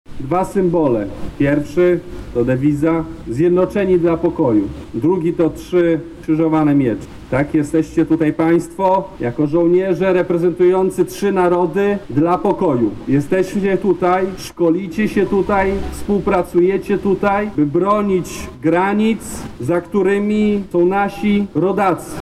• mówi sekretarz stanu w Ministerstwie Obrony Narodowej Marcin Ociepa.